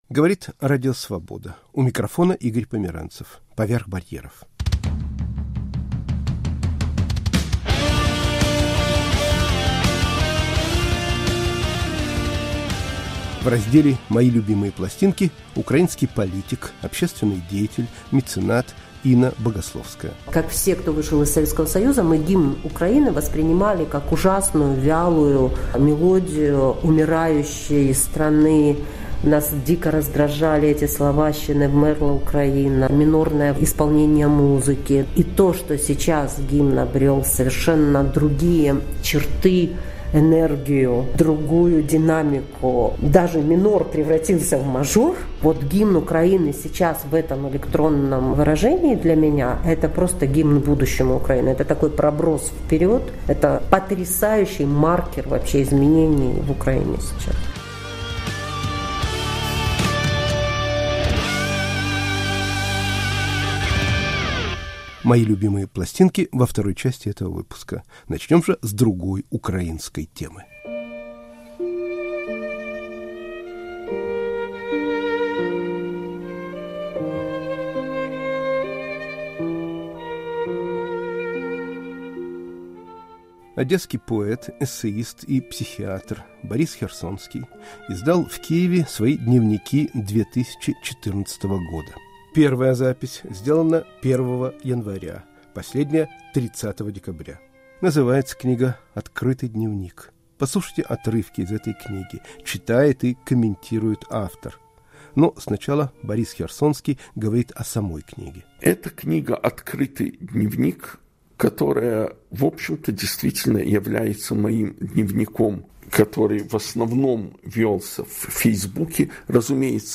Поэт, эссеист, психиатр Борис Херсонский читает и комментирует отрывки из своего дневника 2014 года *** Переписка.